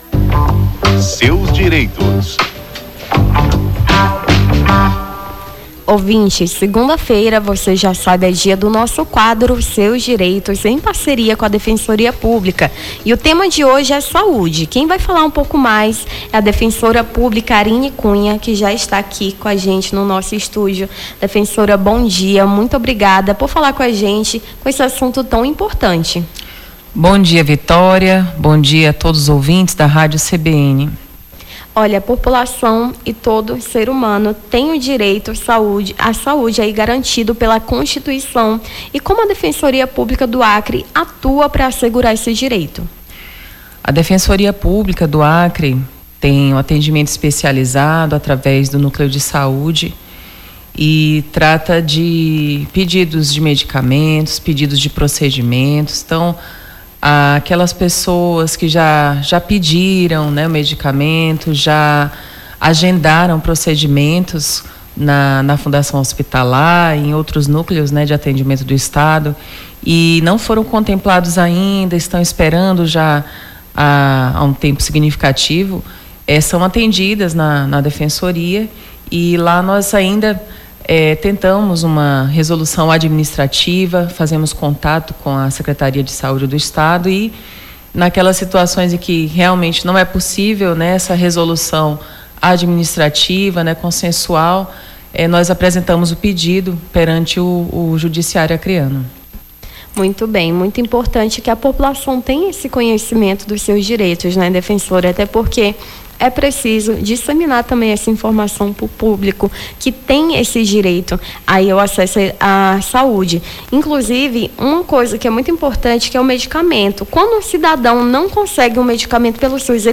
Seus Direitos: Defensora Pública esclarece dúvidas sobre direito à saúde